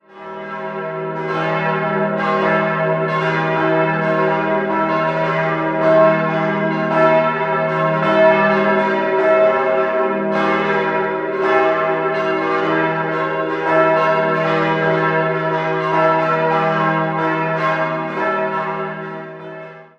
4-stimmiges Salve-Regina-Geläute: e'-gis'-h'-cis'' Die beiden mittleren Glocken wurden 1993 von der Gießerei Perner in Passau gegossen, die kleine stammt aus der selben Gießerei und wurde 1956 angeschafft. Im Jahr 2015 konnte mit der großen Glocke aus der Gießerei Rincker das Geläut komplettiert werden.
Amberg_HeiligeFamilie.mp3